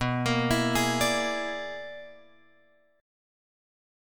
B Minor Major 13th